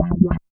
81 BS LICK-L.wav